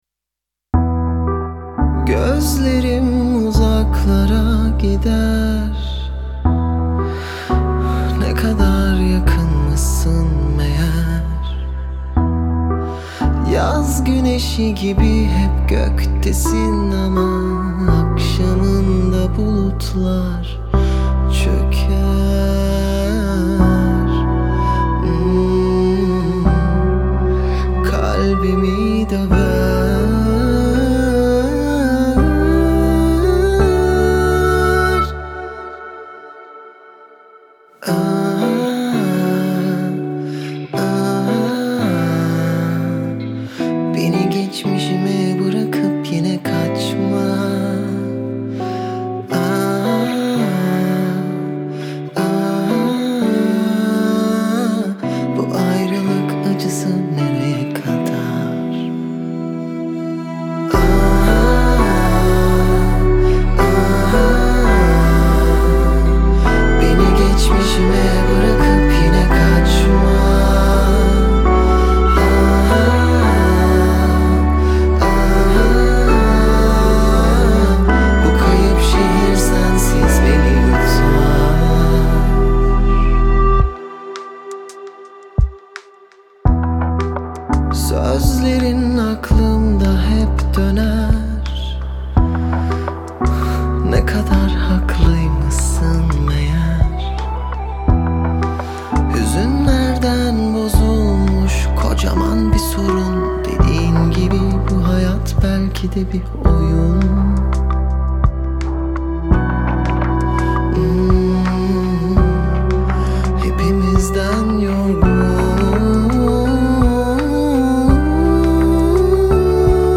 dizi müziği, duygusal hüzünlü rahatlatıcı enerjik şarkı.